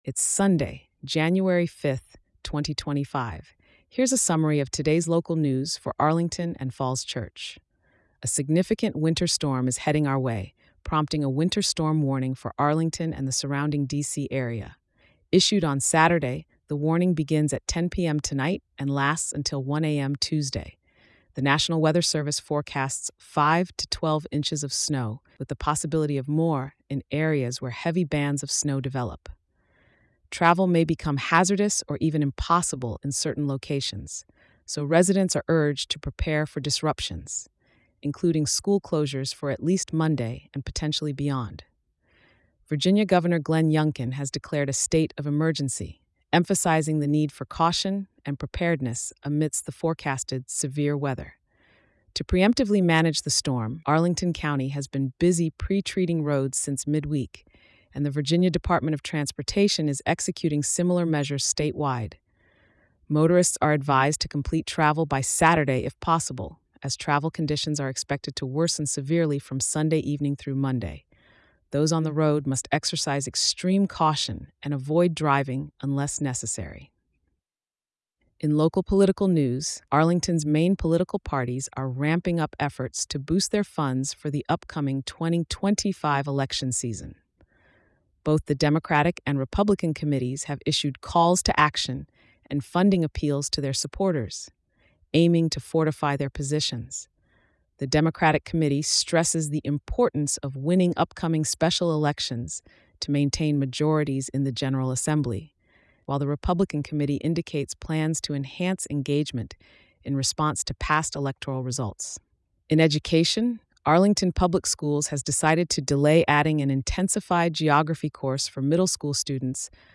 • An audio summary of all news stories (example below)
The summaries, including the daily audio summary, are generated via OpenAI’s latest GPT-4o model.